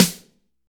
SNR CRISP 02.wav